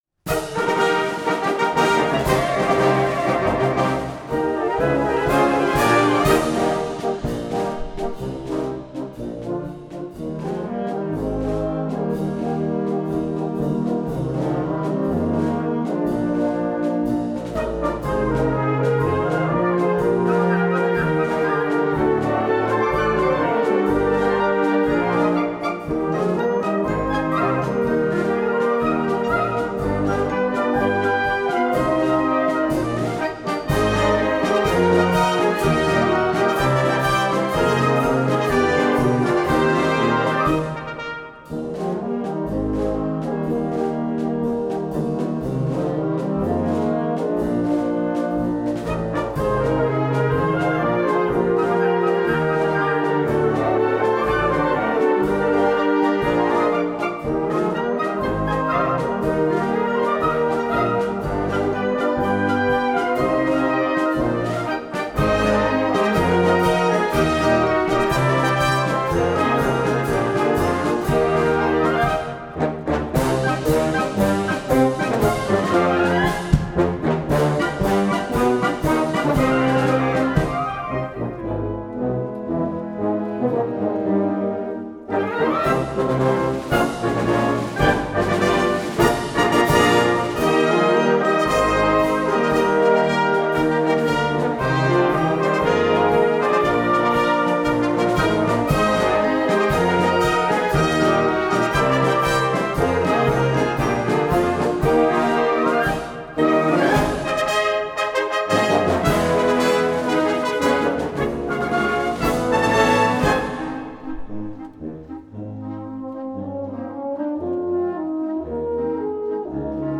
Gattung: Konzertmarsch
Besetzung: Blasorchester